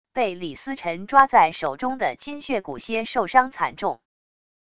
被李思辰抓在手中的金血蛊蝎受伤惨重wav音频生成系统WAV Audio Player